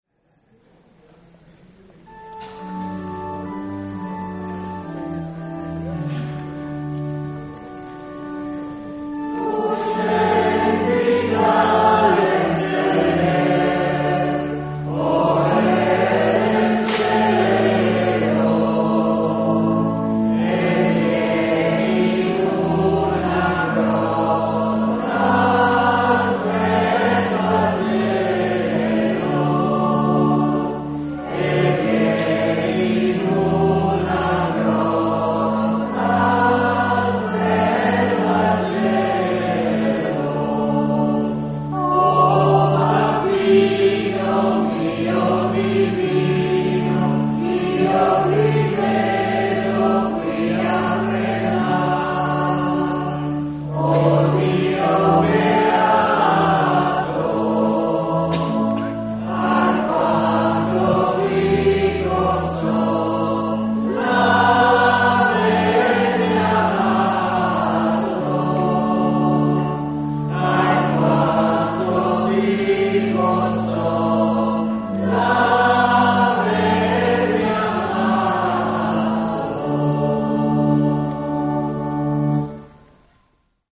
La celebrazione come ogni anno si è svolta nella palestra al piano terra dell’IMFR Gervasutta
accompagnata dalla “Corale Gioconda”, composta da Ammalati di Parkinson di Udine e dintorni.